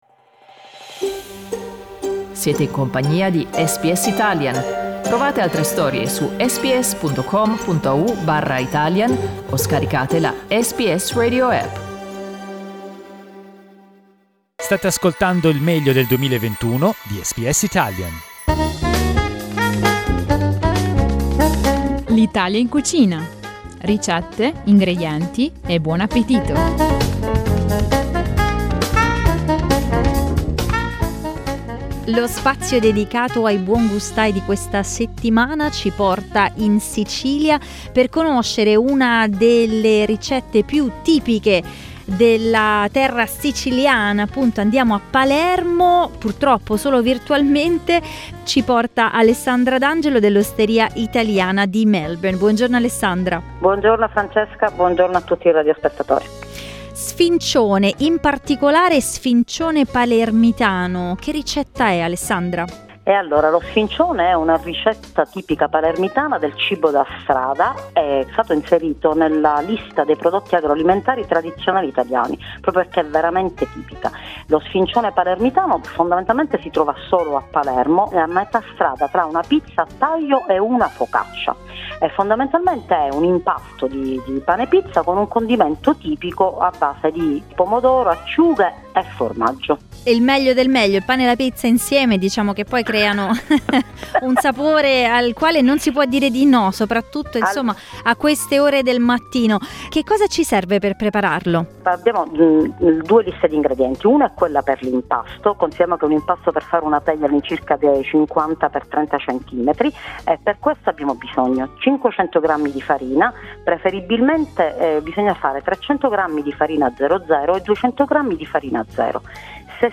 Ogni settimana "L'Italia In Cucina" ci offre una ricetta raccontata da uno chef.